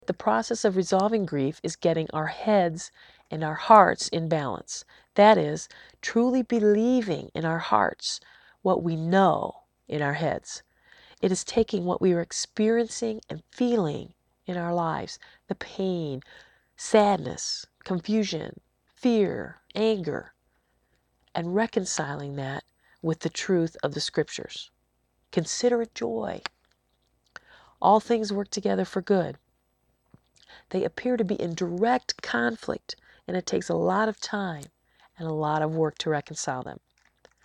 I was also given the privilege of presenting a lesson for our Ladies Inspiration Day at our church in April, 2004. The subject was dealing with struggles, and I used examples from the book of Job as well as my own experiences to share some of what God has taught me about grief and suffering.